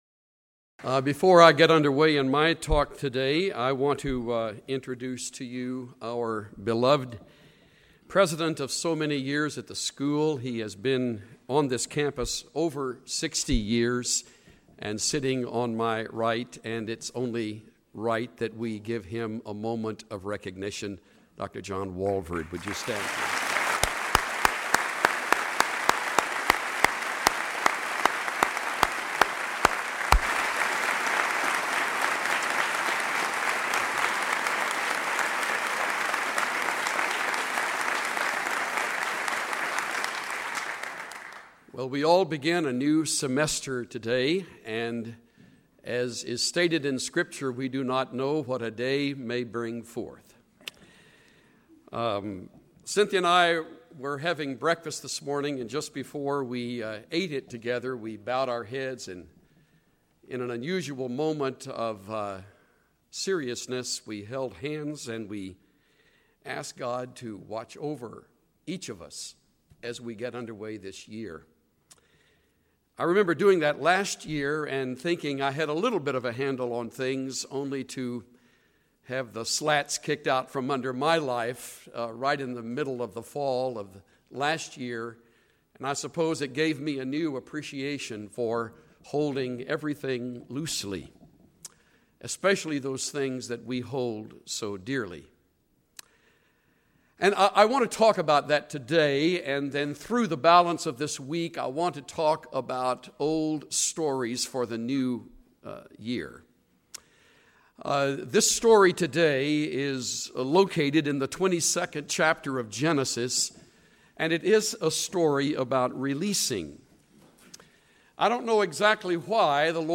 Chuck Swindoll exposits the story of Abraham and Isaac and explains how it signifies a test of Abraham's face.